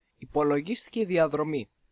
(male)